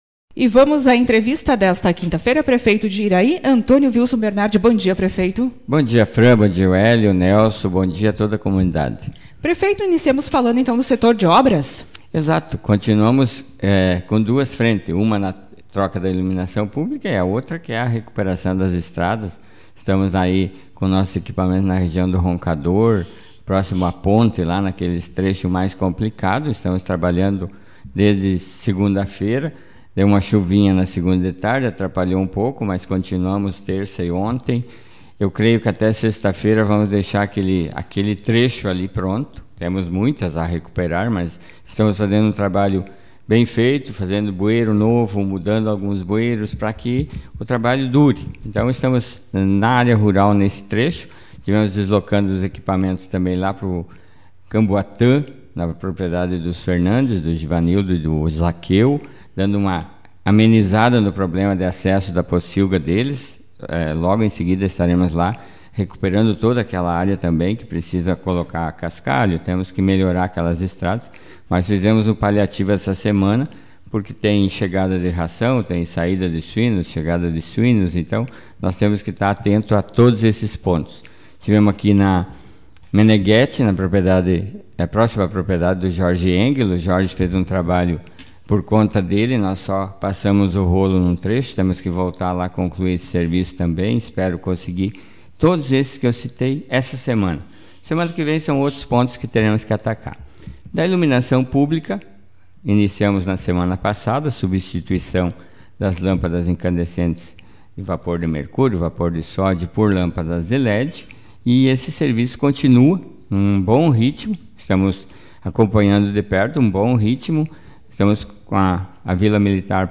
Executivo municipal de Iraí realiza obras de melhorias na área urbana e rural Autor: Rádio Marabá 16/03/2023 Manchete O prefeito de Iraí, Antonio Vilson Bernardi, participou nesta manhã do programa Café com Notícias e atualizou as informações sobre os trabalhos da administração municipal.